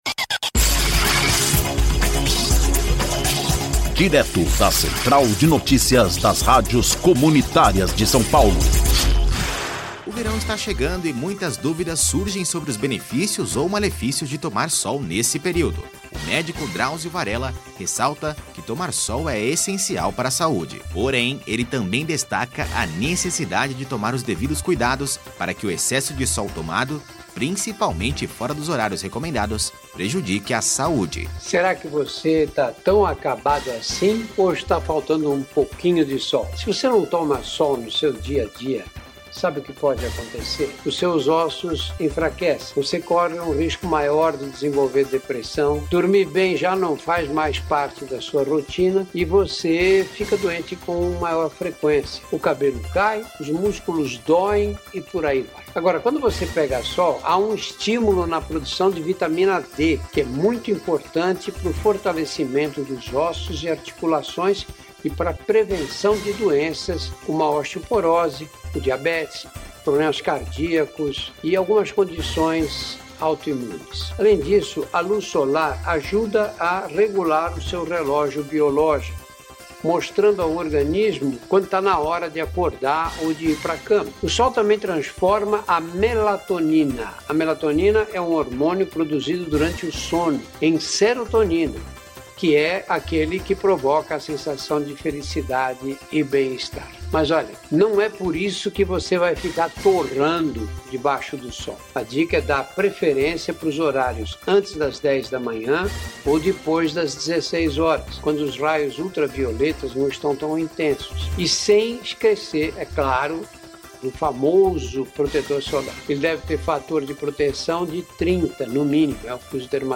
INFORMATIVO:
CENTRAL-DE-NOTICIAS-ONDA-SOL-DR-DRAUZIO-VARELLA.mp3